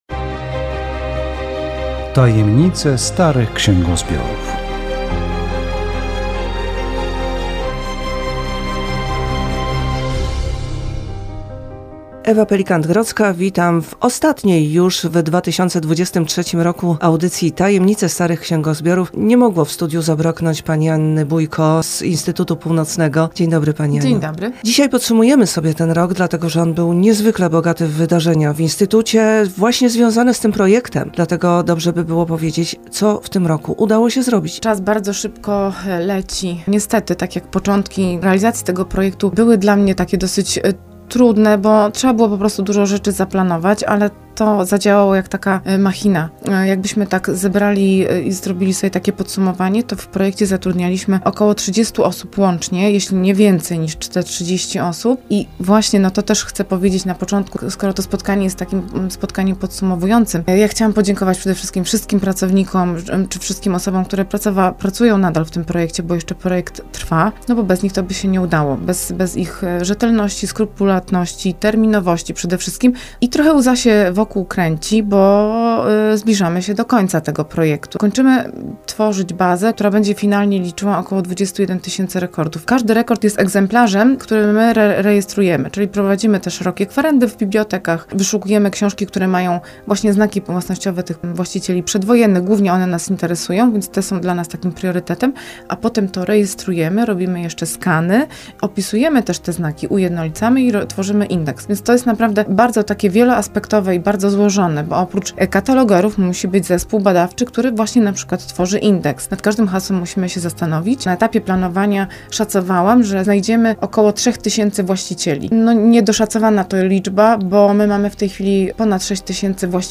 Audycja radiowa "Tajemnice starych księgozbiorów".